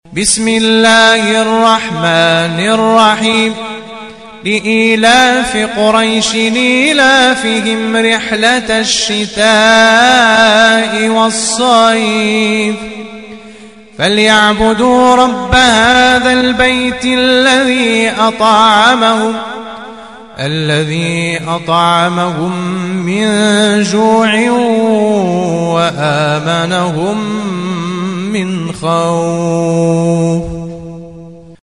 تلاوة